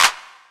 BOOMIN CLAP 4 CRISP.wav